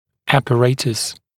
[ˌæpə’reɪtəs][ˌэпэ’рэйтэс]аппарат